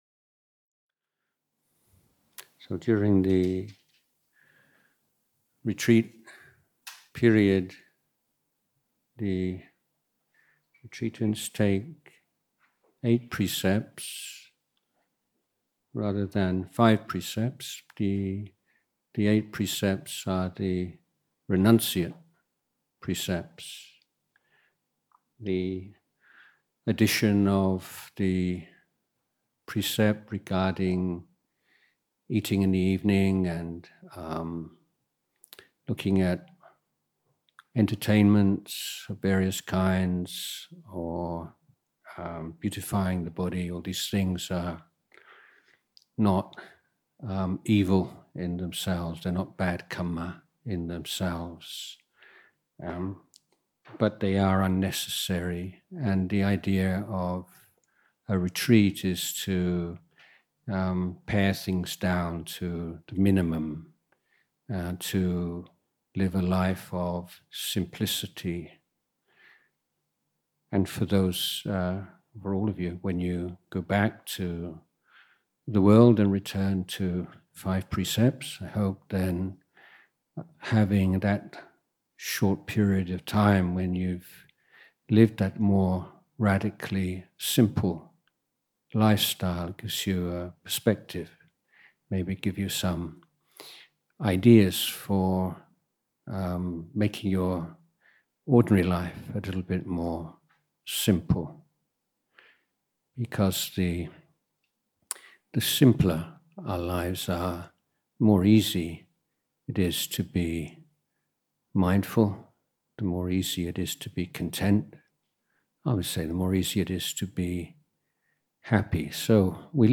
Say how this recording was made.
English Retreat at Bahn Boon, Pak Chong, Nakhon Rachasima, 20-24 March 2024